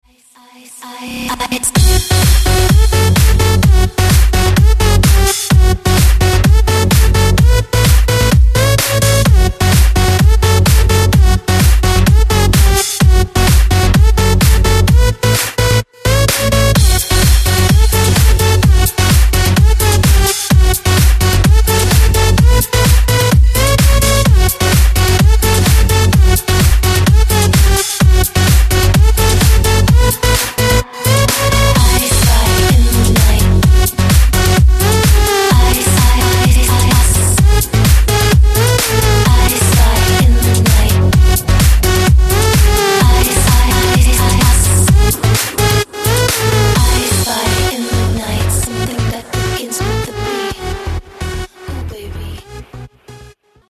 Подстиль: Electro House / Electro